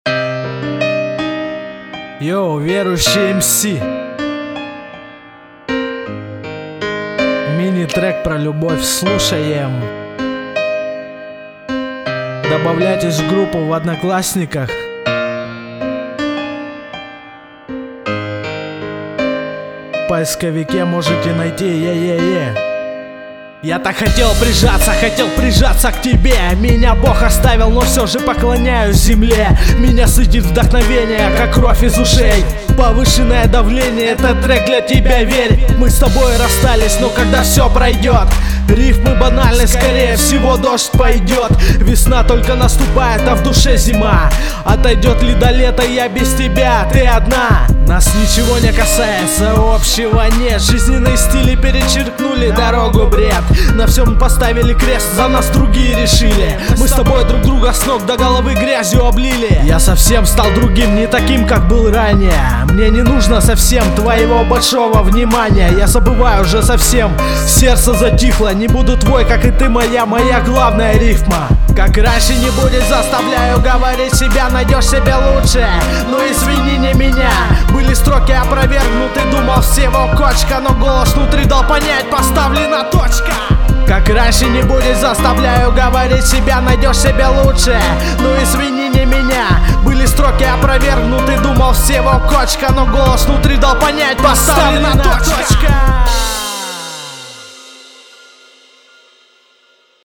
Лирика про любовь [42]